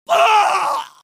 crying-men-sound